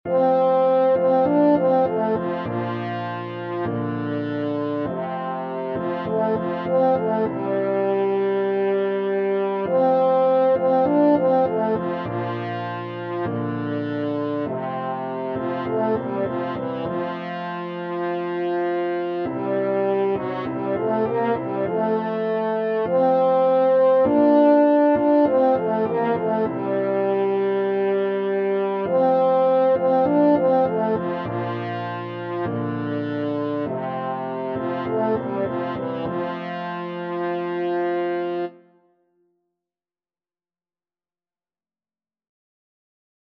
4/4 (View more 4/4 Music)
Classical (View more Classical French Horn Music)